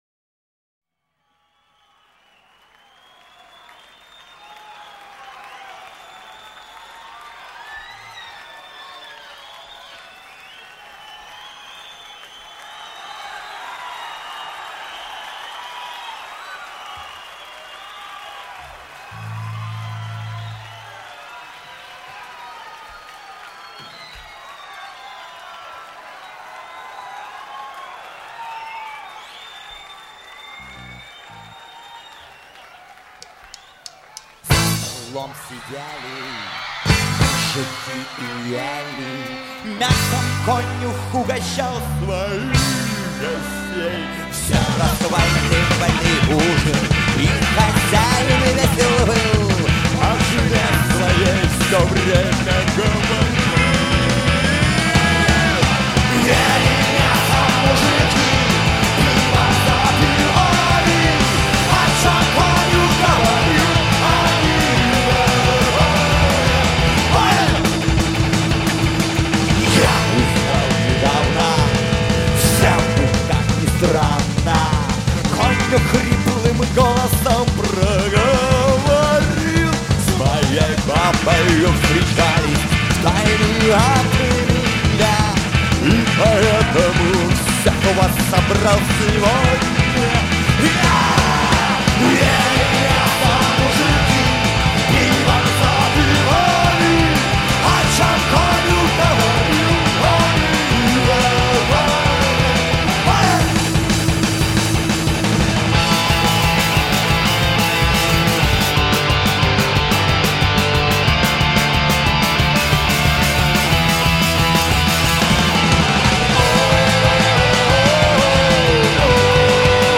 Метал
Жанр: Метал / Рок